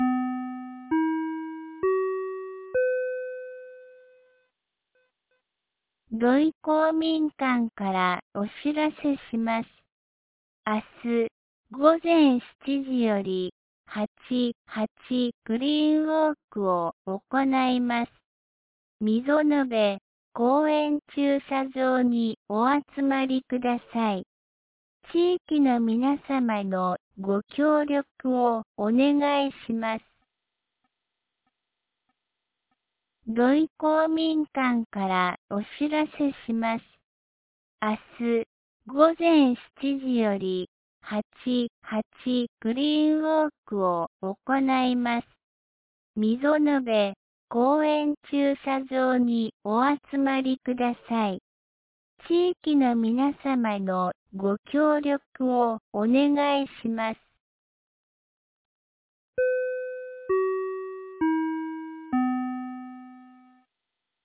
2025年08月07日 17時11分に、安芸市より土居、僧津へ放送がありました。